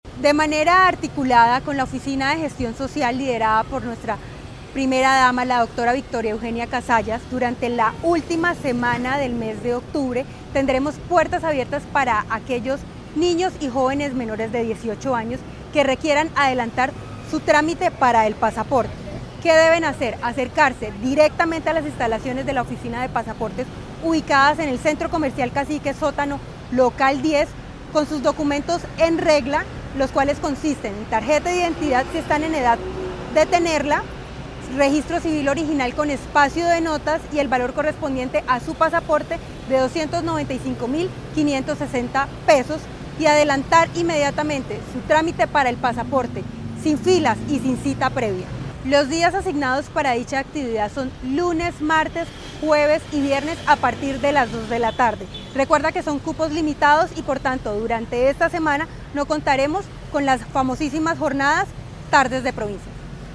Waleska Quintero, Directora de la oficina de Pasaportes de la gobernación de Santander